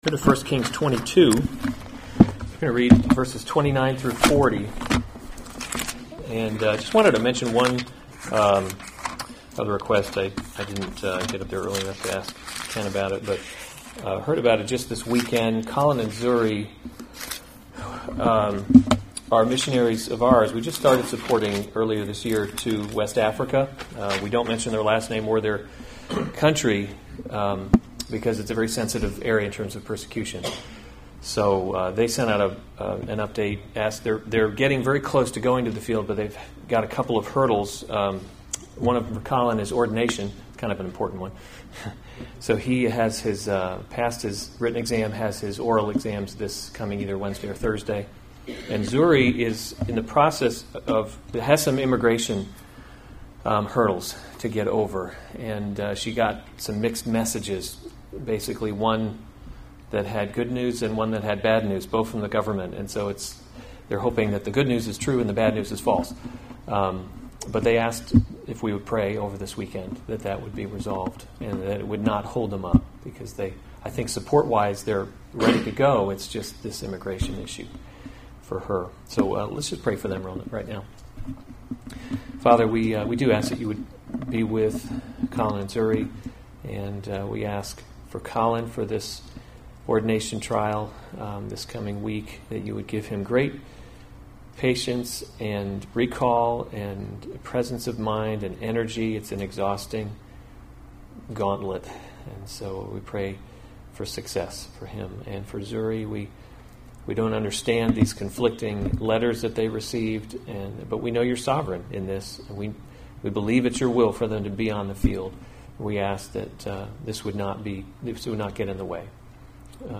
July 27, 2019 1 Kings – Leadership in a Broken World series Weekly Sunday Service Save/Download this sermon 1 Kings 22:29-40 Other sermons from 1 Kings Ahab Killed in Battle […]